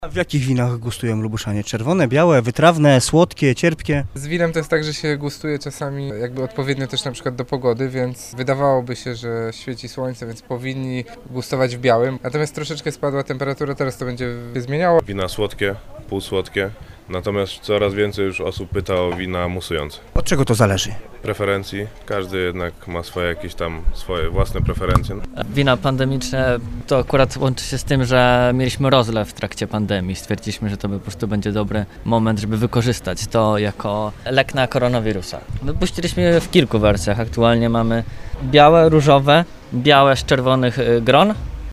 Wybór wina łączy się z pogodą i indywidualnymi preferencjami mówią producenci: